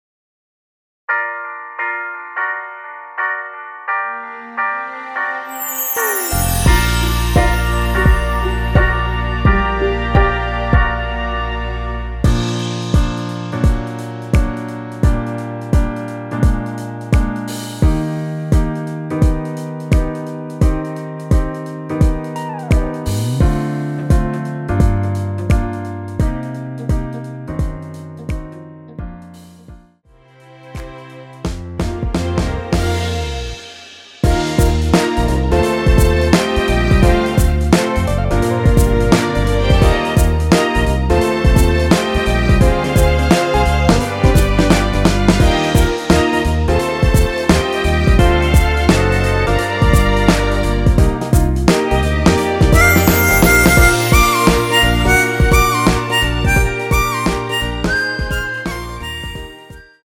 엔딩이 페이드 아웃이라서 노래하기 편하게 엔딩을 만들어 놓았으니 코러스 MR 미리듣기 확인하여주세요!
원키에서(+2)올린 MR입니다.
Bb
앞부분30초, 뒷부분30초씩 편집해서 올려 드리고 있습니다.